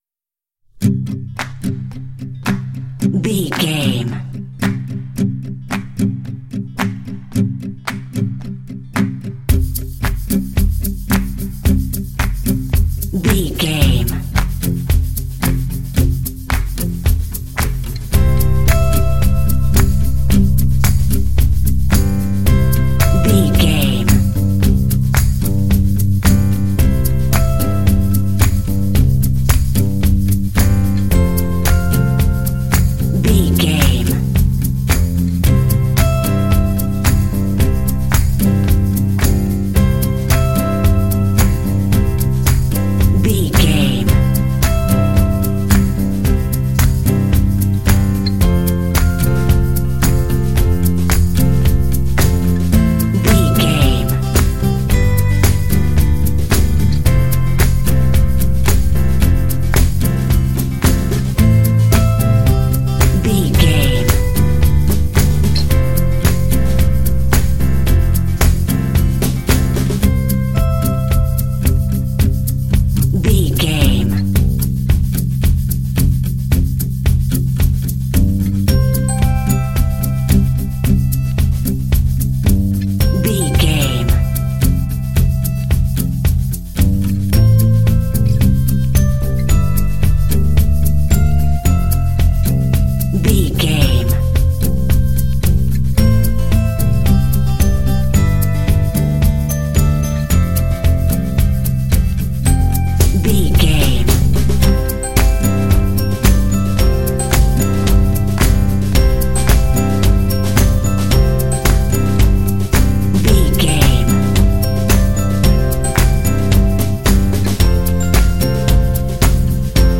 Uplifting
Ionian/Major
bright
joyful
acoustic guitar
bass guitar
electric guitar
drums
percussion
electric piano
indie
pop
contemporary underscore